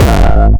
fluffy kick.wav